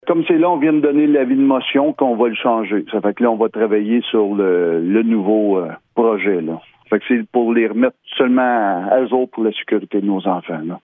Voici les propos du maire de Bouchette, Steve Lefebvre :